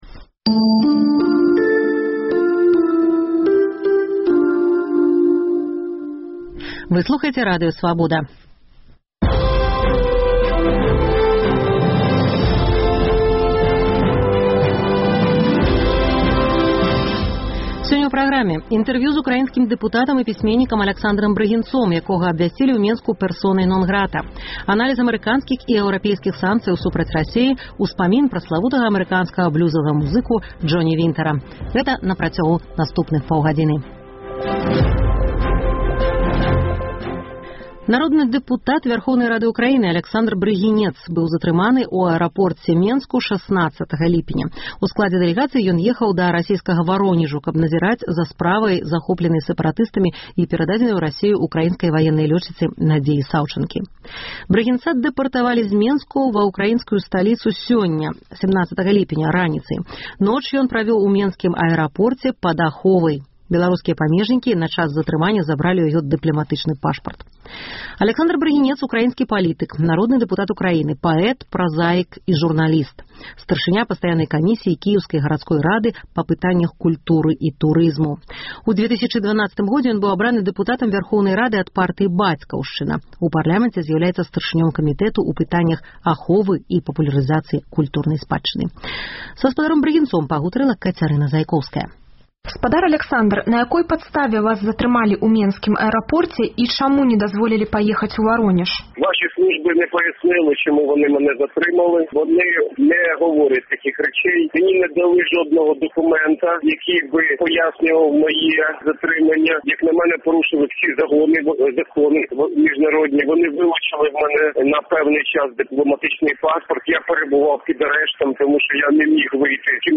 Адказы даюць амэрыканскія і беларускія аналітыкі. У праграме таксама інтэрвію з украінскім дэпутатам і пісьменьнікам Аляксандрам Брыгінцом, якога абвясьцілі ў Менску пэрсонай нон-грата, погляд на праблему банкруцтваў прыватных прадпрыемстваў і на забудовы паркавых зонаў камэрцыйным жыльлём, разважаньні з нагоды юбілею канцлера ЗША Ангелы Мэркель.